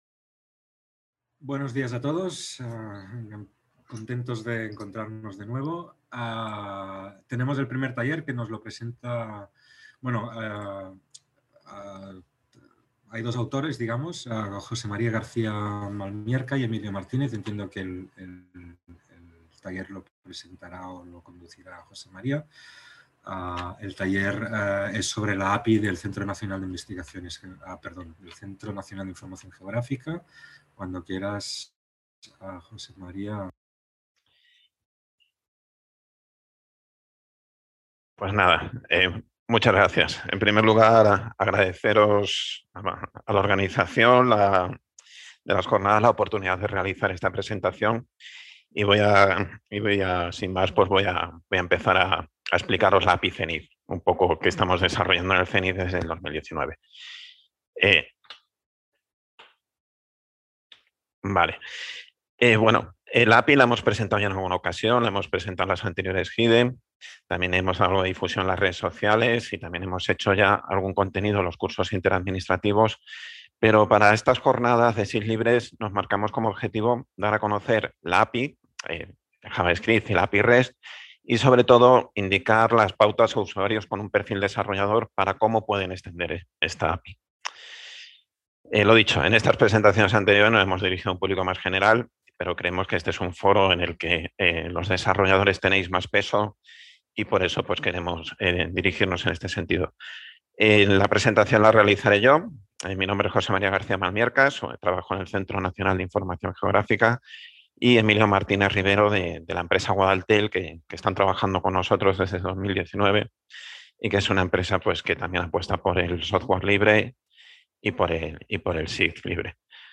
Taller